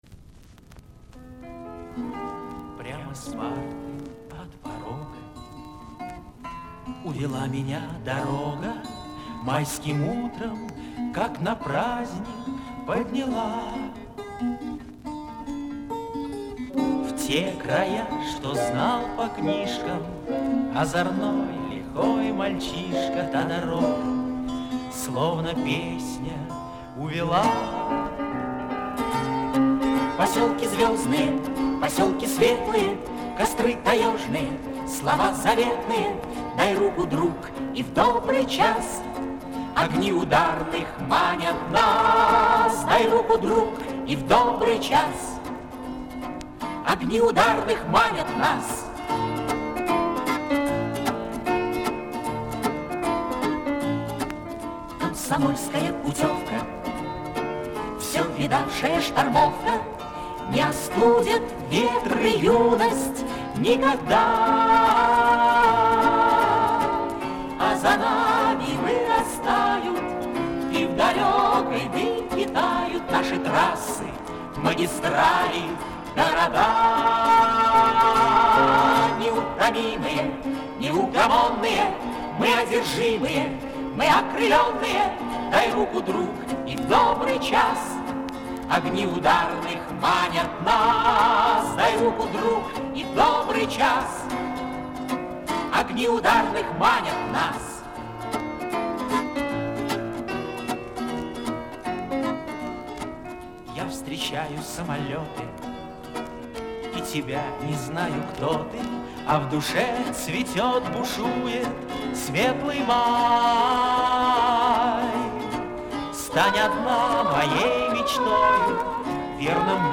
тот же мужской и женский голос на переднем плане